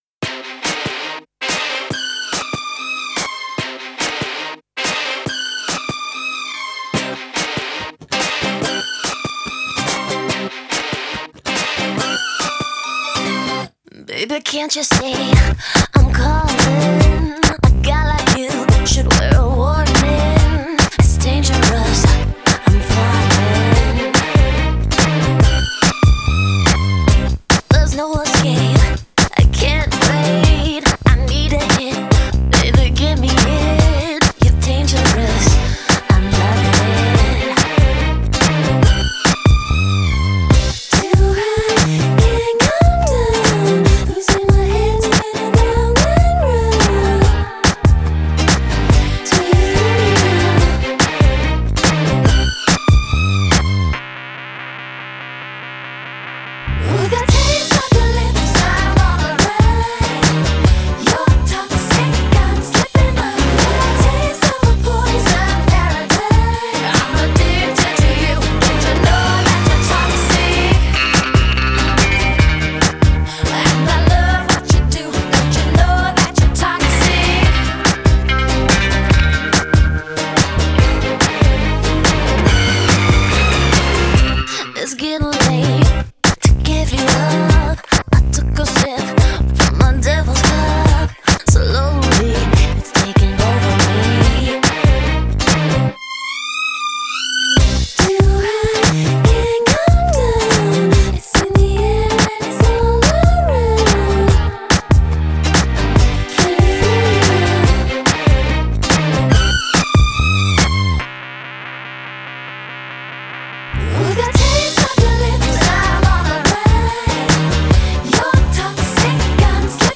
Pop 2000er